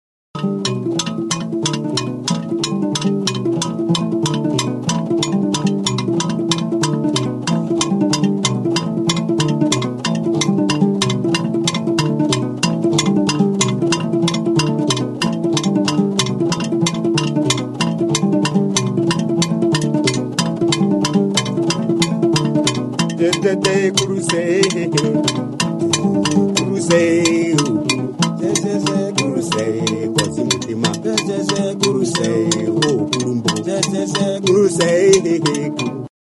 Instrumentos de músicaENNANGA
Cordófonos -> Pulsados (con dedos o púas)
ÁFRICA -> RUANDA
'Harpa de arco' motako harpa da. 8 soka ditu.